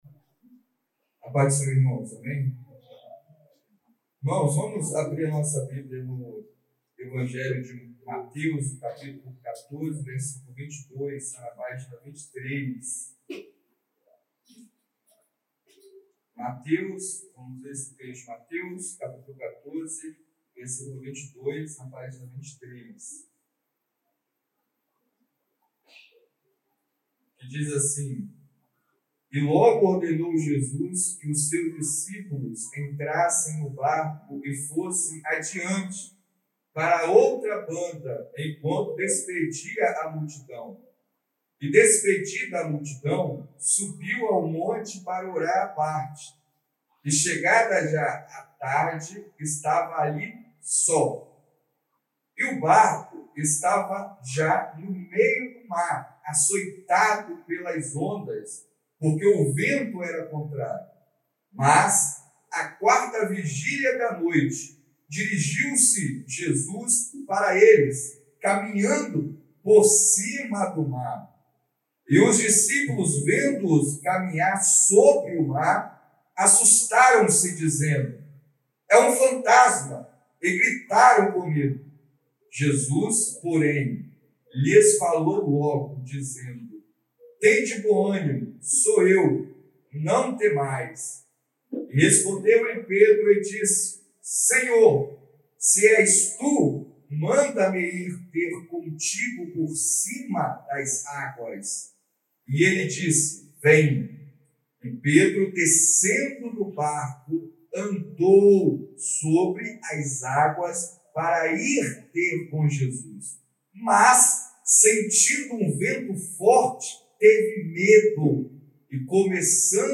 Culto de Adoração